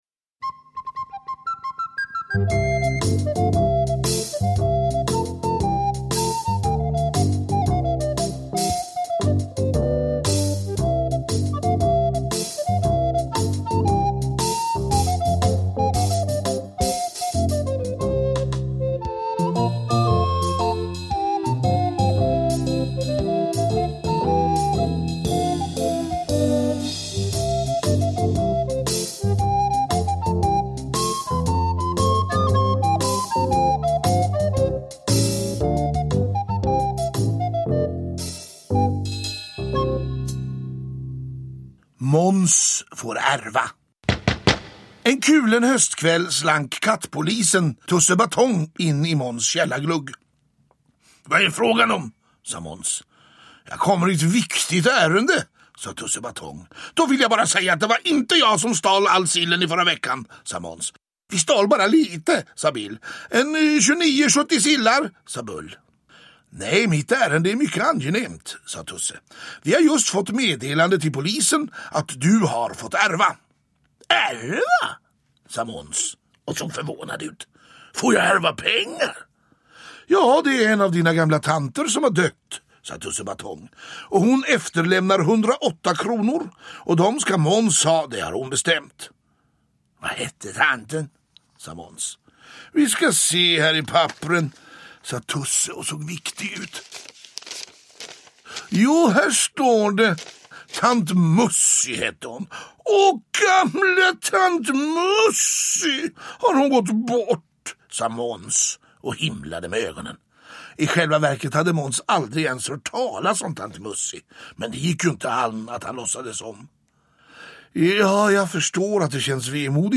Peter Harryson läser med stor inlevelse Gösta Knutssons klassiska berättelser om Pelle Svanslös, Maja Gräddnos, elake Måns, Bill och Bull och alla de övriga katterna på Åsgränd i Uppsala.
Uppläsare: Peter Harryson